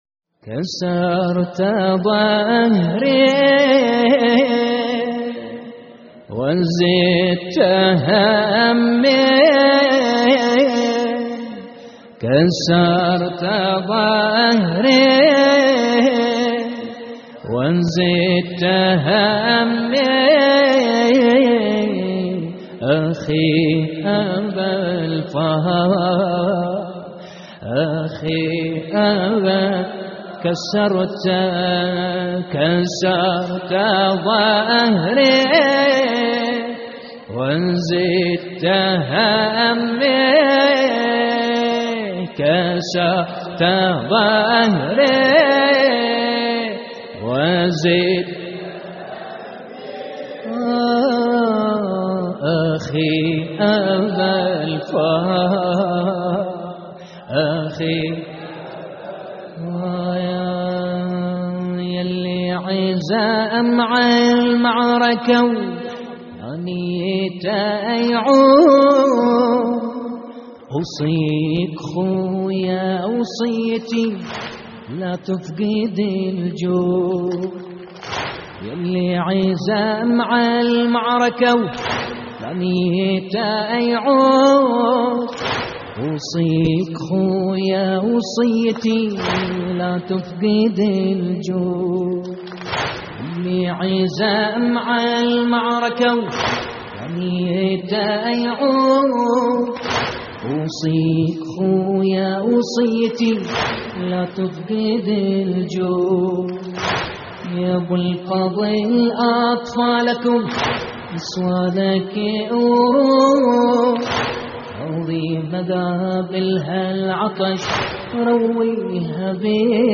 اللطميات الحسينية